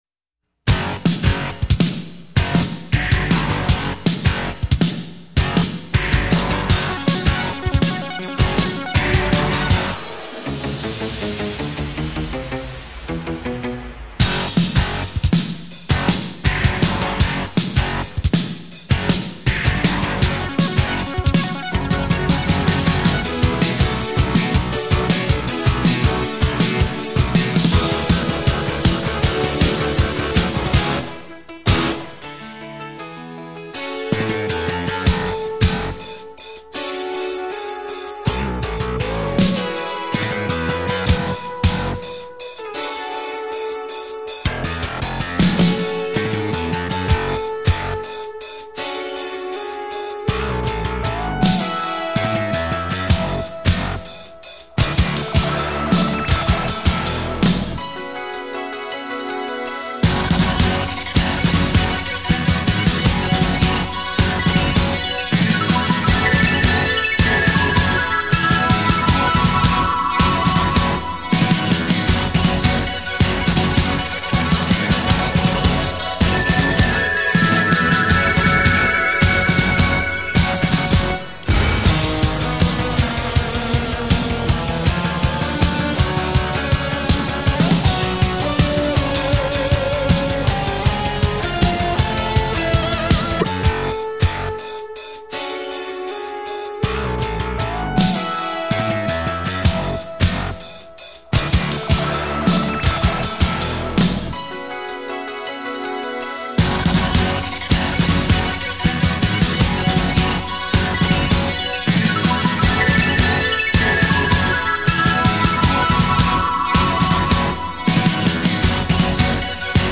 Esta canción es instrumental, y no tiene letra.